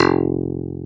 CLV_ClavDBF_2 3a.wav